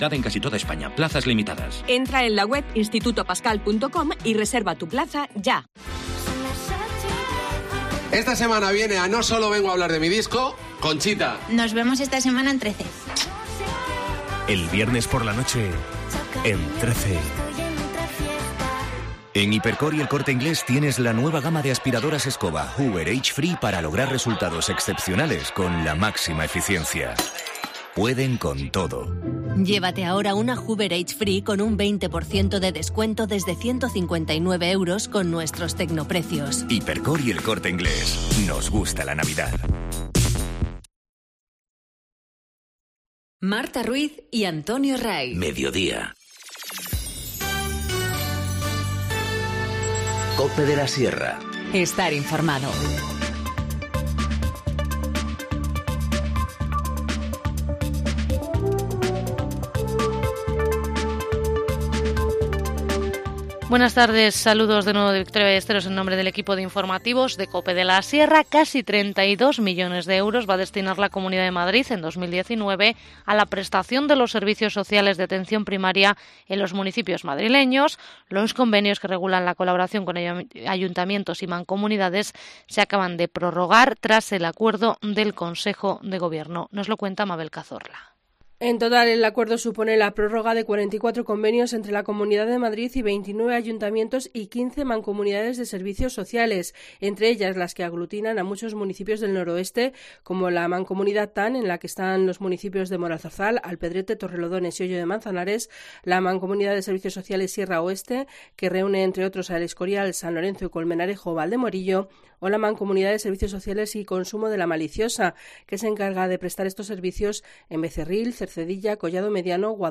Informativo Mediodía 28 dic- 14:50h